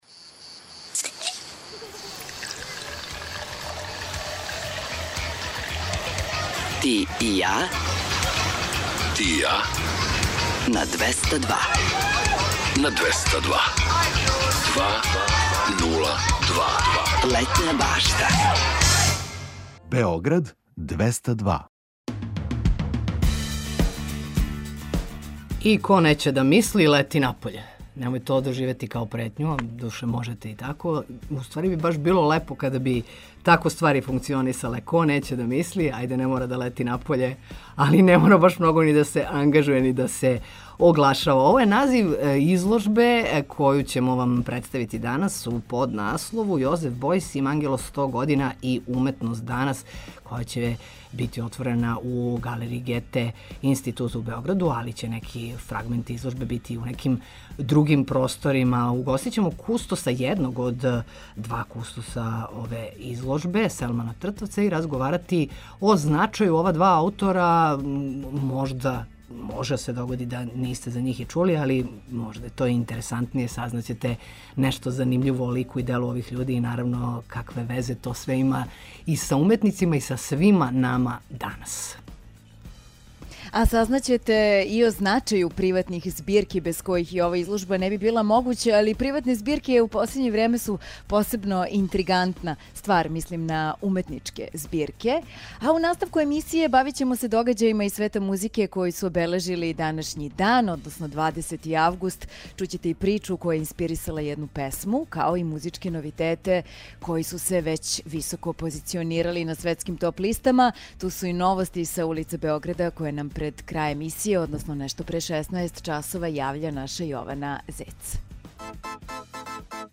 У наставку емисије, бавимо се догађајима из света музике који су обележили данашњи дан. Чућете и причу која је инспирисала једну песму, као и музичке новитете који се високо позиционирају на светским топ листама.